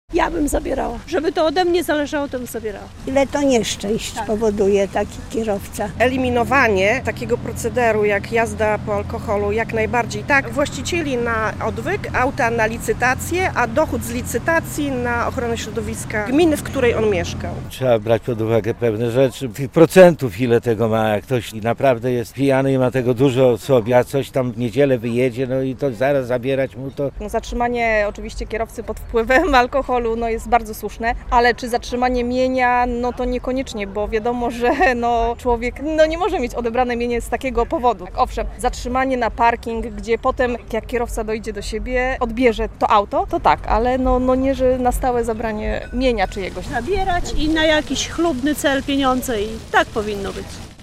relacja
Niektórzy zapytani przez nas mieszkańcy naszego regionu uważają, że przepisy są zbyt surowe - inni przyznają, że konfiskata pojazdów pijanych kierowców jest konieczna.